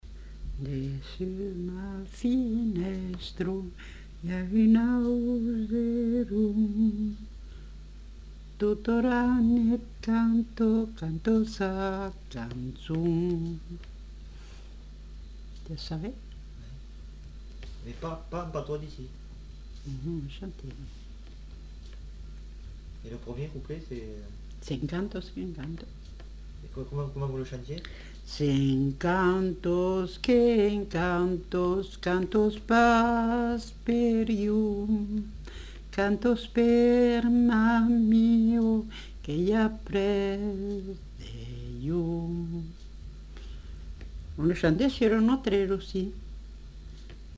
Lieu : Montauban-de-Luchon
Genre : chant
Effectif : 1
Type de voix : voix de femme
Production du son : chanté
Description de l'item : fragment ; 1 c. ; refr.